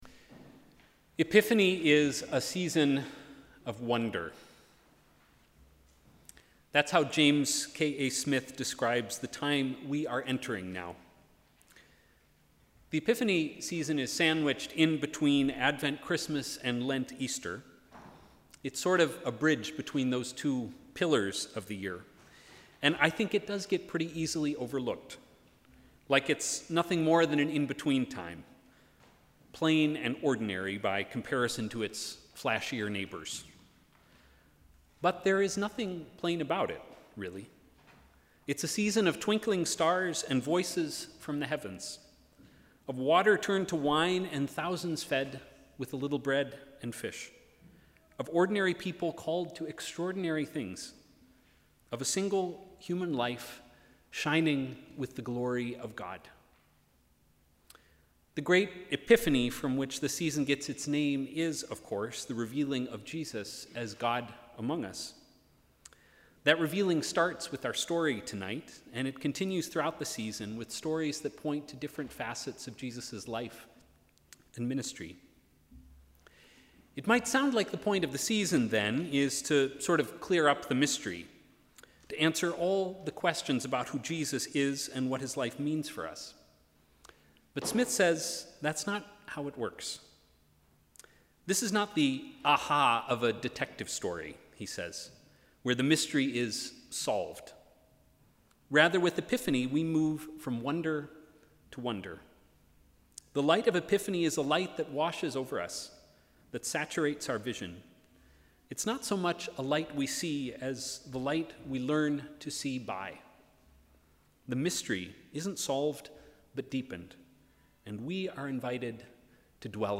Sermon: ‘An invitation to wonder’
SermonEpiphanyLS221.mp3